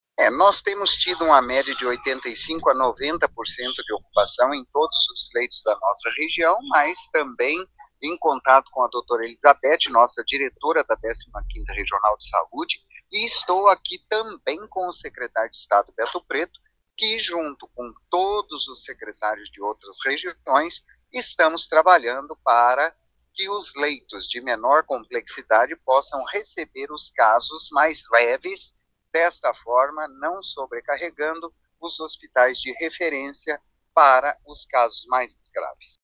O secretário de Saúde Antônio Carlos Nardi diz que todas as unidades básicas de saúde registram aumento de casos, mas poucos com complicações.